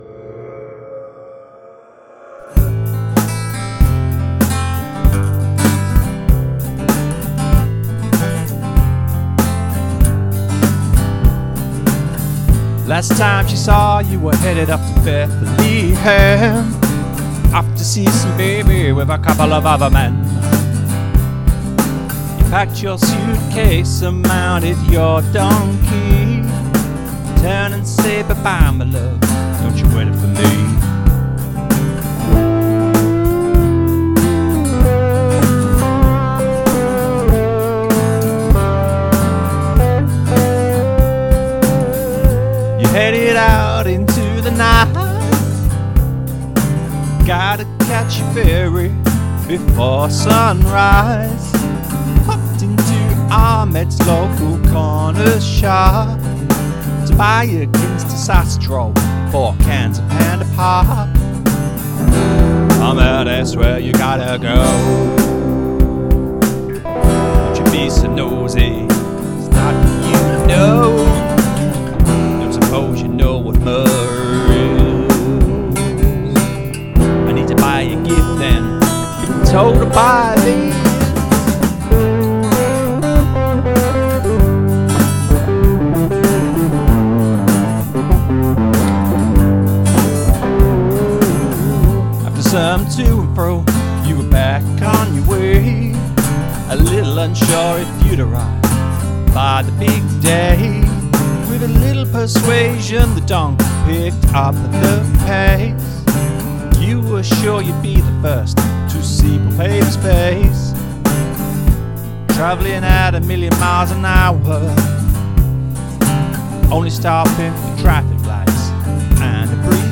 The guitar work too evokes such loveliness.